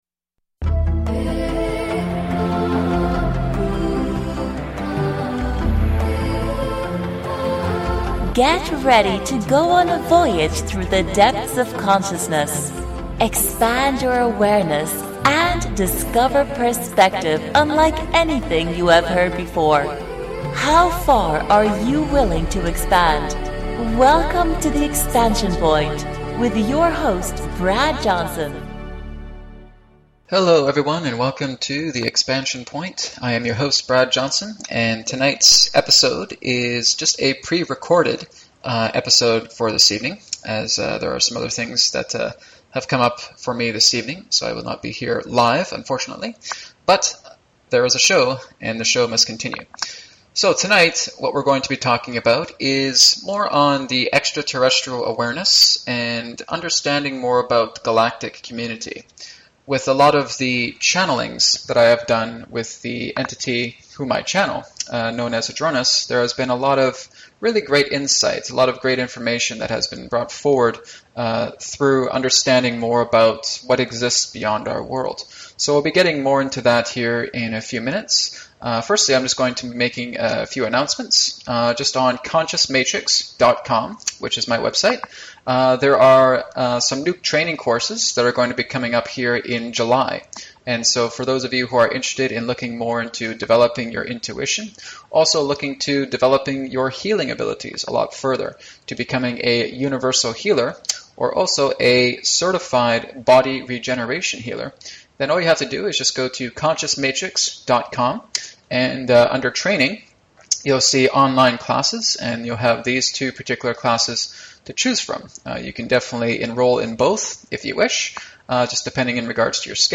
Talk Show Episode
Live call ins will be available near the end of our show!